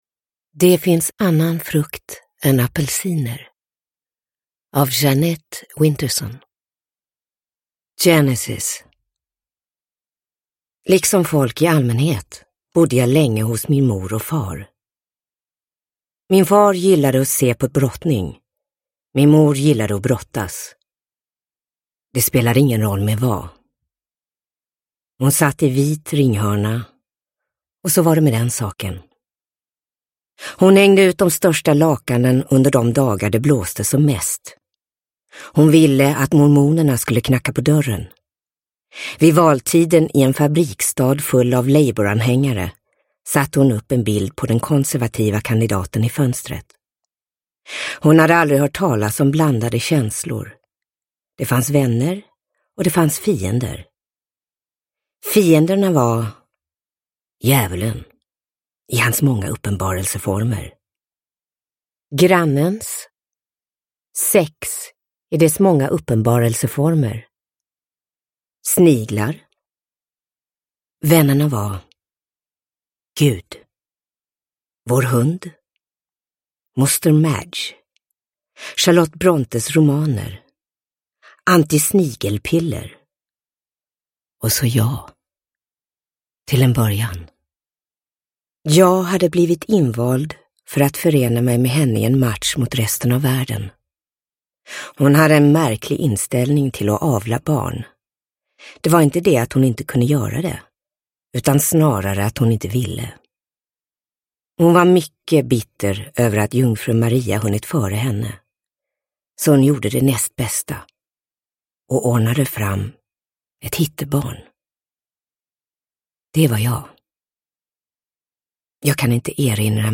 Det finns annan frukt än apelsiner – Ljudbok – Laddas ner
Uppläsare: Amanda Ooms